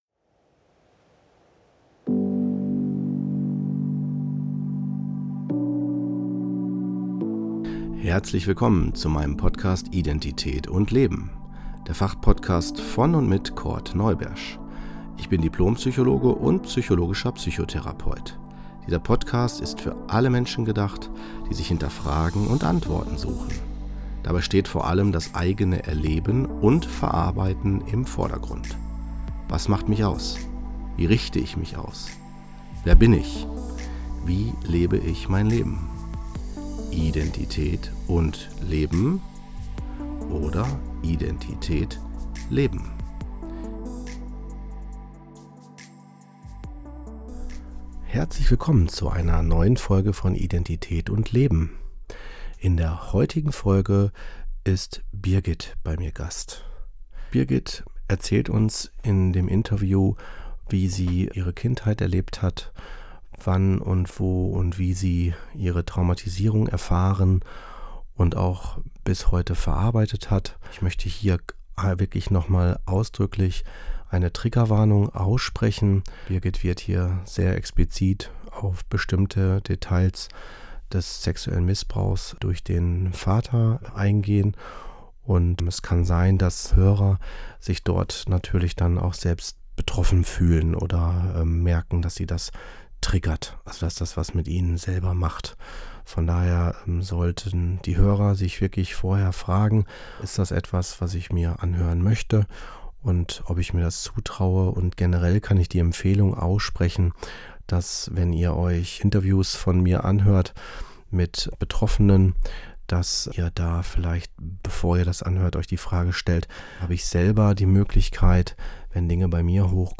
Das Interview ist in 6 Folgen aufgeteilt.